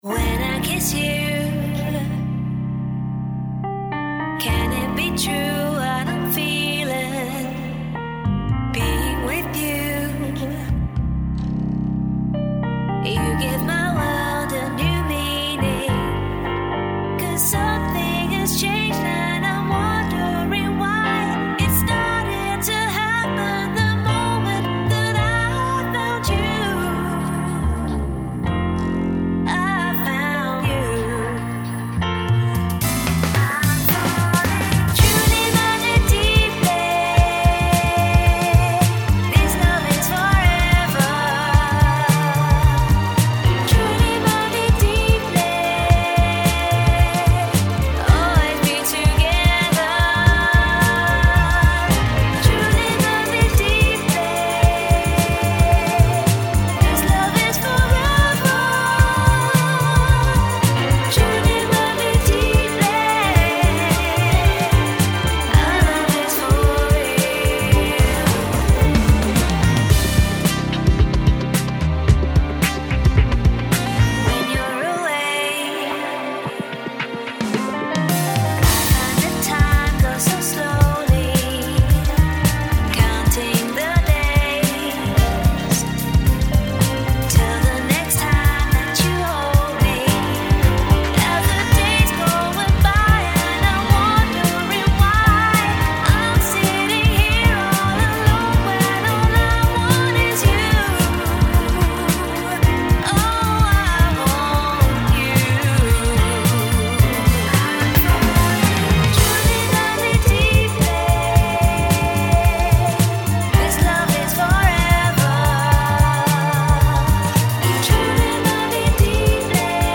dance/electronic
RnB
Soul & swing
NuJazz